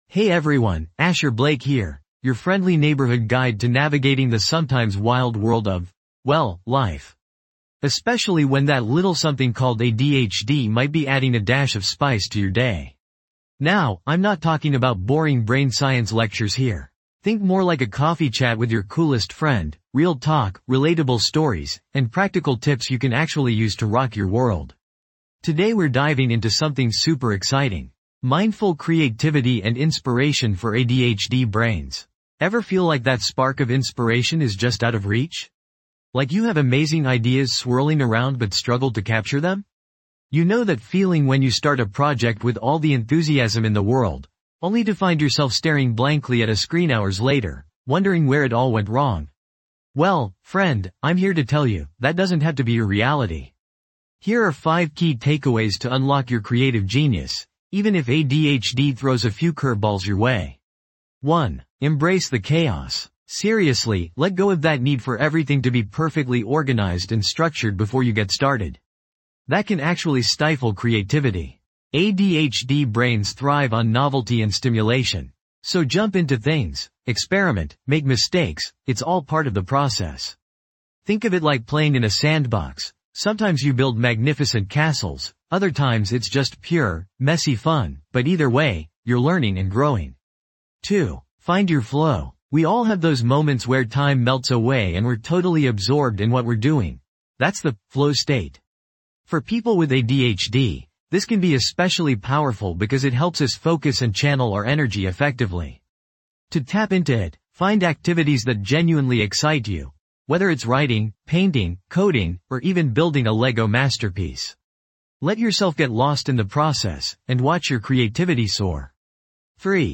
Personal Development Mental Health Productivity This podcast is created with the help of advanced AI to deliver thoughtful affirmations and positive messages just for you.